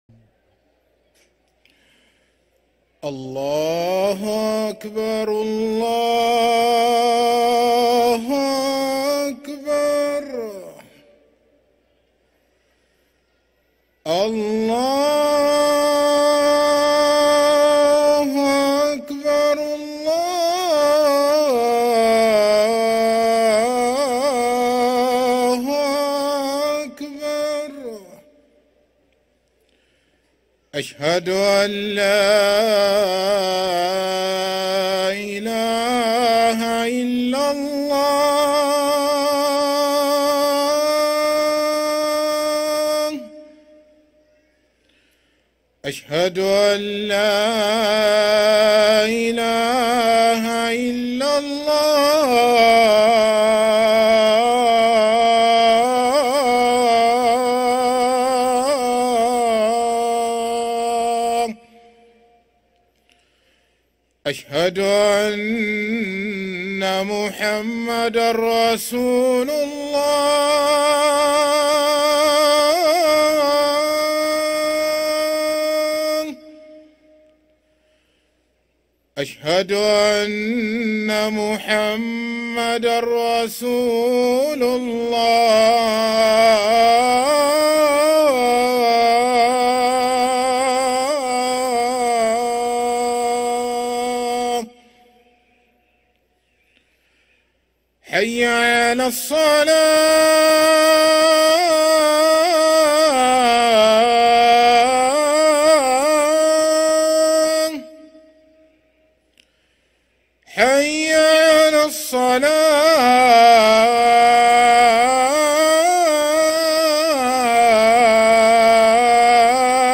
أذان المغرب للمؤذن سعيد فلاته السبت 25 محرم 1445هـ > ١٤٤٥ 🕋 > ركن الأذان 🕋 > المزيد - تلاوات الحرمين